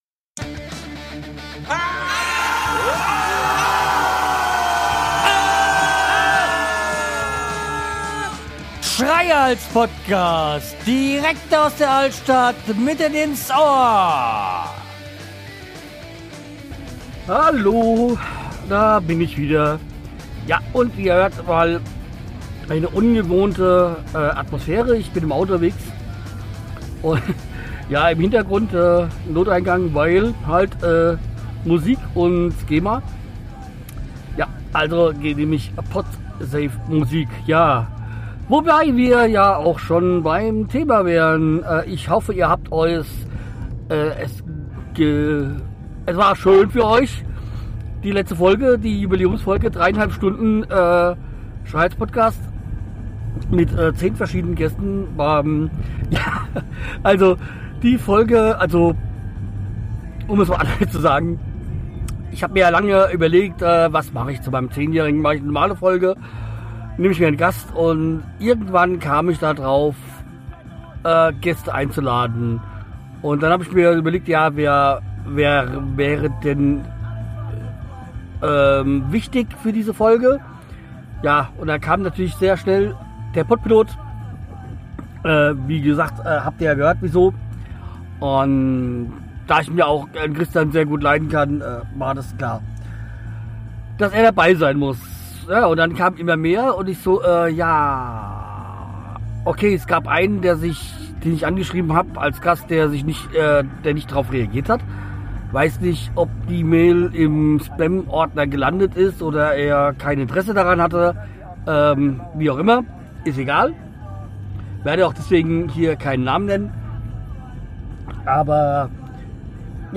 Ja, heute dürft Ihr mir beim Autofahren zuhören und dabei erzähle ich von den aufnähmen zur Jübiläumsfolge.